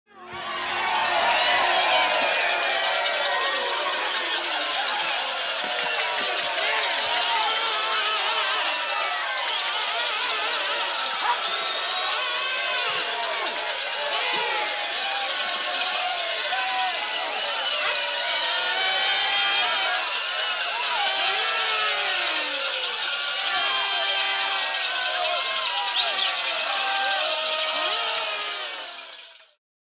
Звуки вечеринки, парада
Люди кричат и свистят на масштабном собрании